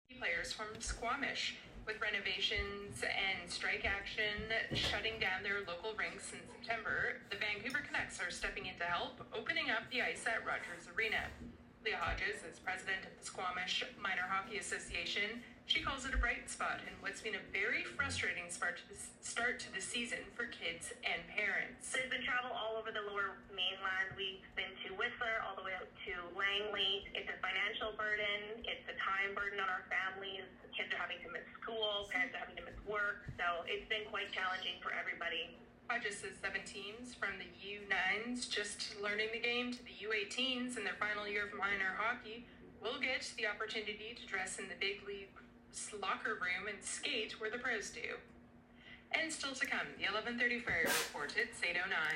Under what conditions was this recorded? at Rogers Arena